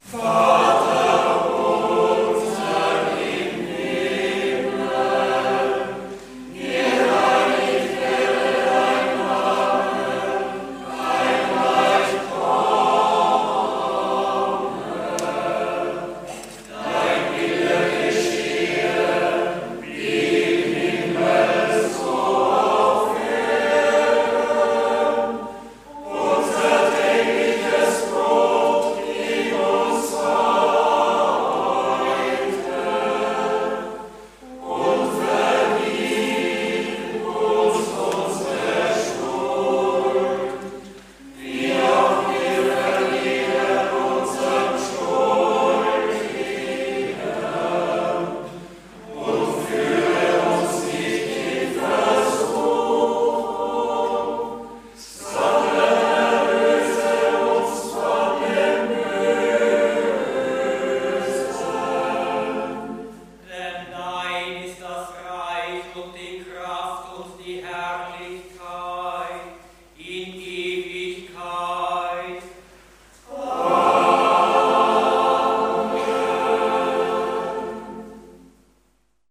Ruprechtskirche Live